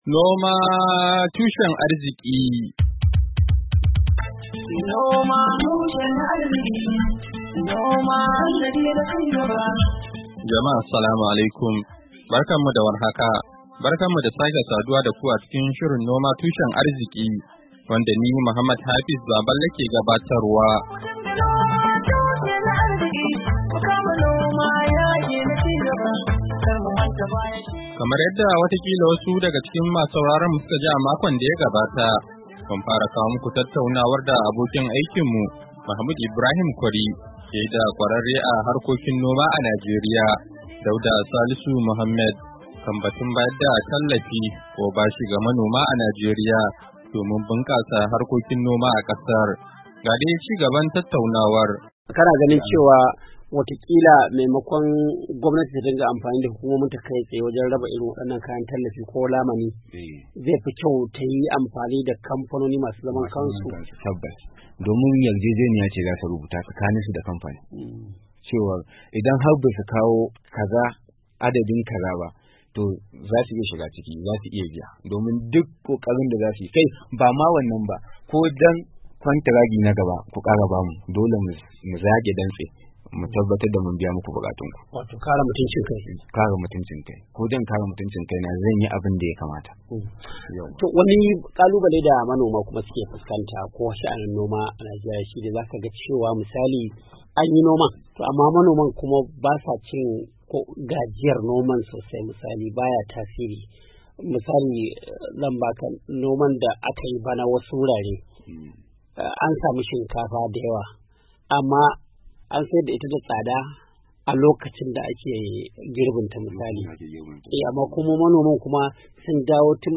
NOMA TUSHEN ARZIKI: Hira Da Kwararre Kan Harkokin Noma A Kan Batun Bunkasa Noma A Najeriya PT 4.mp3